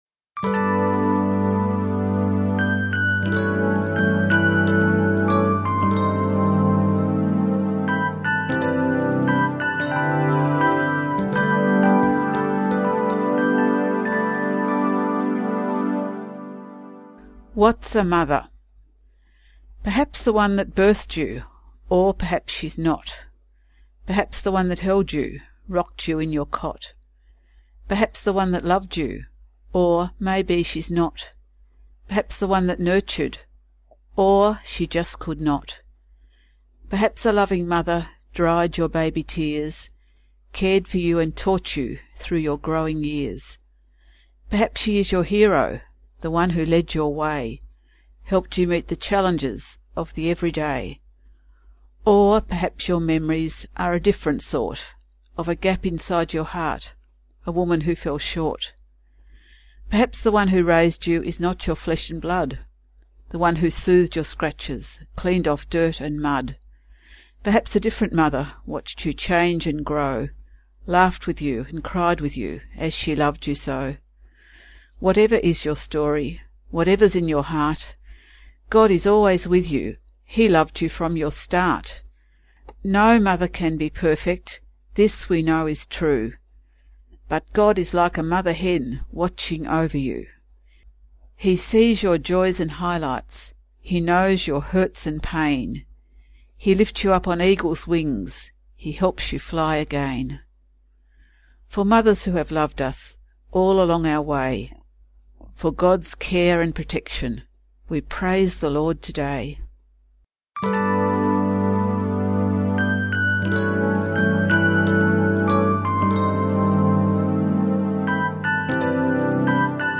Poem “Whats a mother?”
Today 10 May 2015, Mothers Day, Songs of Hope honoured mothers. Not all mothers are the same, and this poem touches on that.